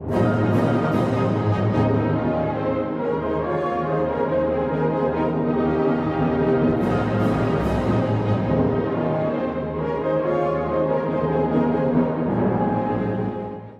感情が爆発するような弦の刻み、そして勝利に向かうような上昇音型が特徴的です。